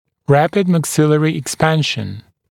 [‘ræpɪdmæk’sɪlərɪ ɪk’spænʃn] [‘ræpɪd ‘pælətl ɪk’spænʃn][‘рэпид мэк’силэри ик’спэншн] [‘рэпид ‘пэлэтл ик’спэншн]быстрое расширение верхней челюсти